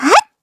Taily-Vox_Jump_kr.wav